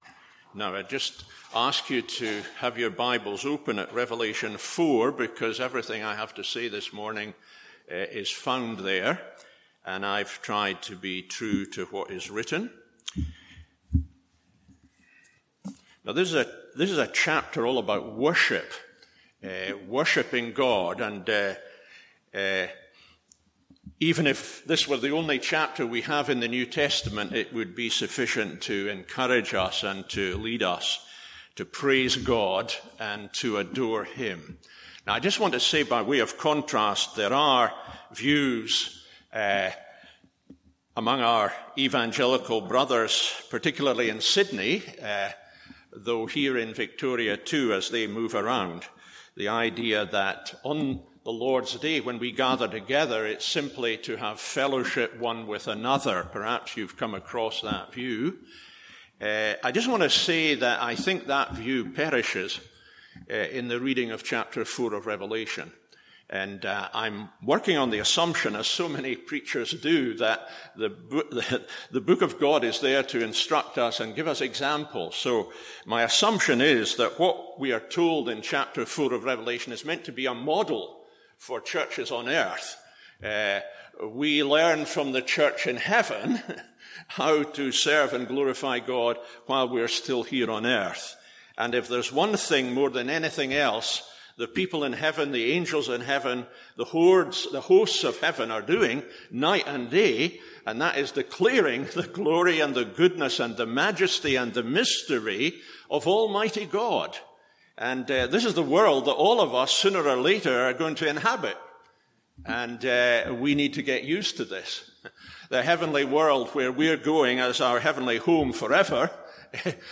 MORNING SERVICE Rev 4…